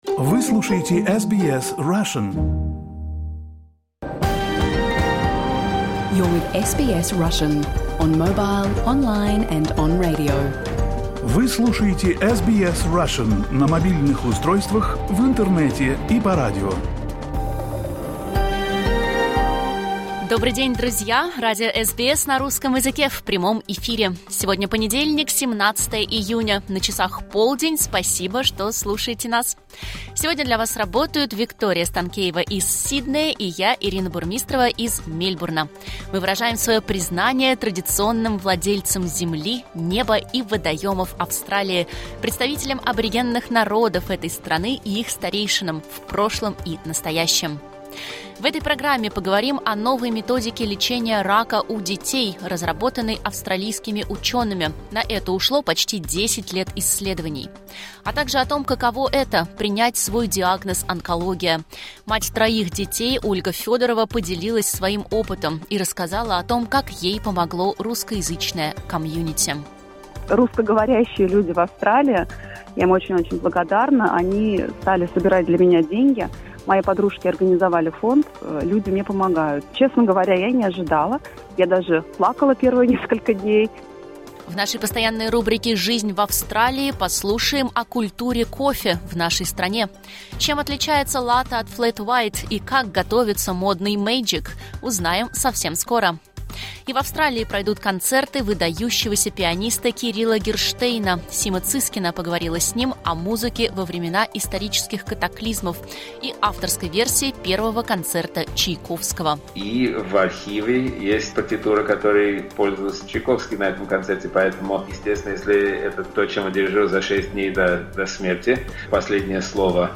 You can listen to SBS Russian program live on the radio, on our website and on the SBS Audio app.